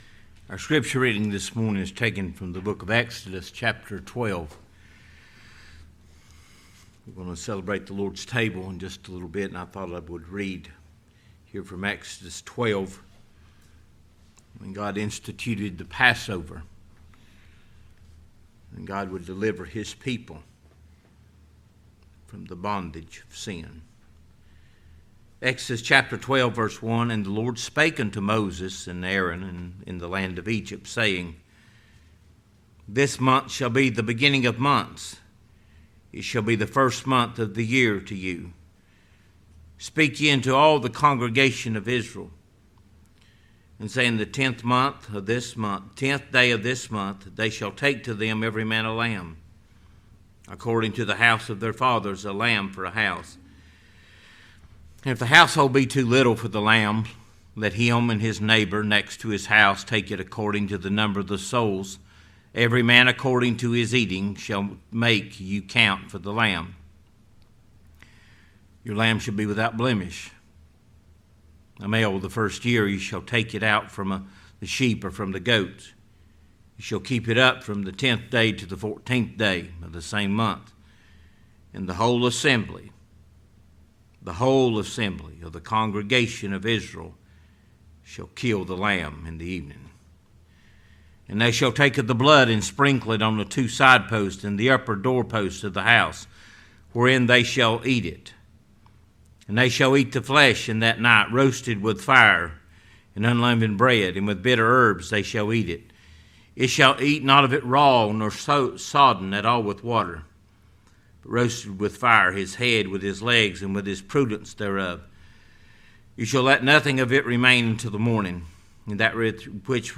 The Lord's table | SermonAudio Broadcaster is Live View the Live Stream Share this sermon Disabled by adblocker Copy URL Copied!